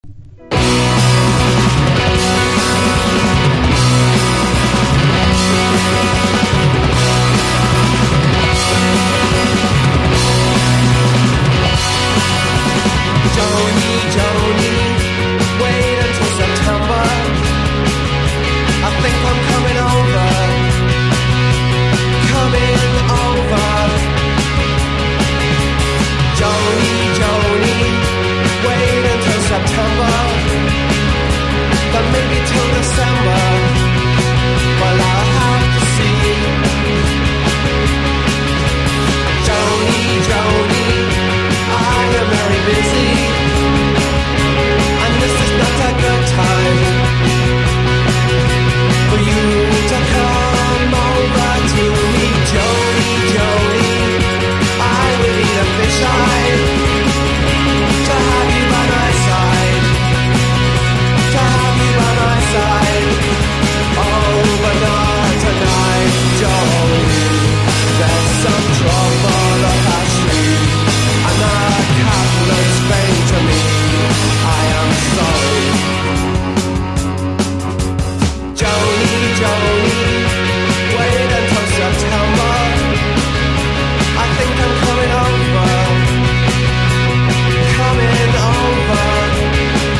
GARAGE ROCK
ガレージ・ポップ・サウンドでキャッチーな一曲。